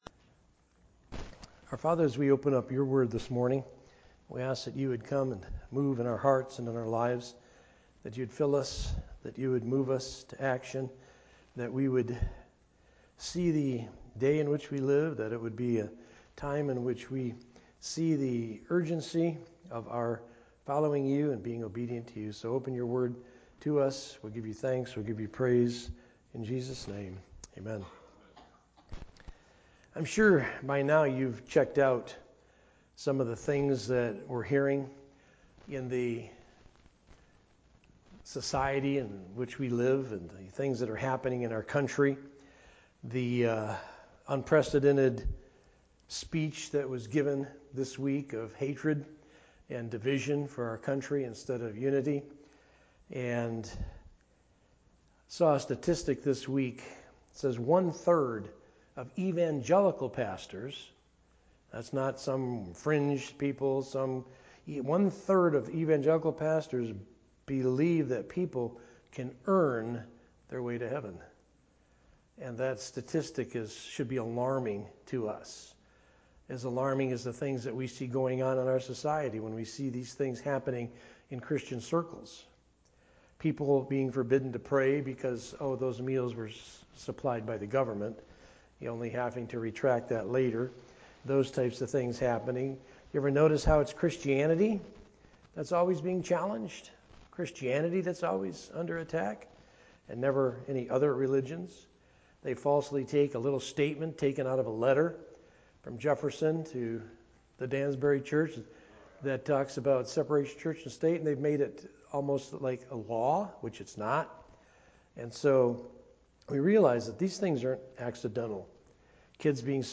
A message from the series "General."
Sermon